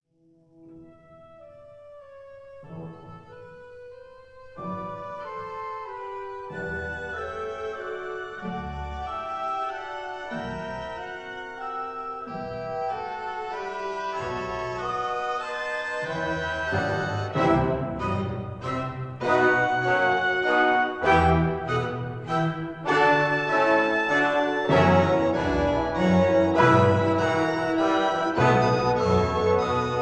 Allegro energico e passionato recorded in April 1950